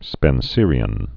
(spĕn-sîrē-ən)